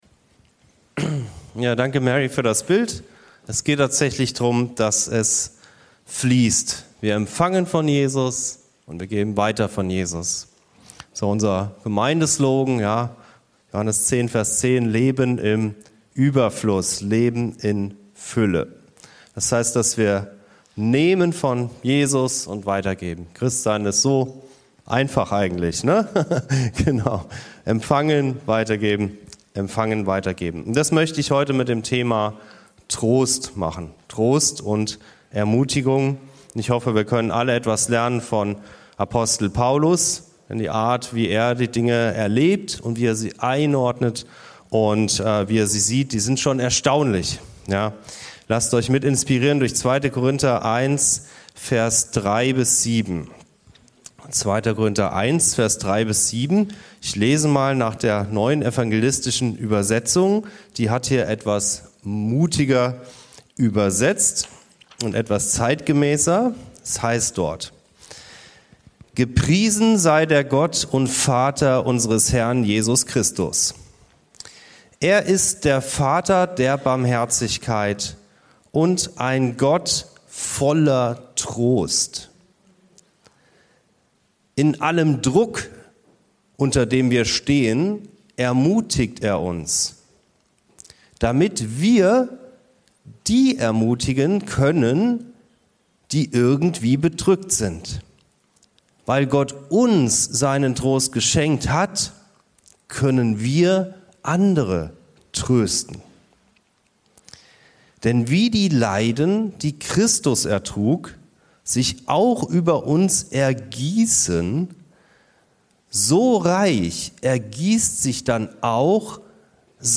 Der Gott des Trostes ~ Anskar-Kirche Hamburg- Predigten Podcast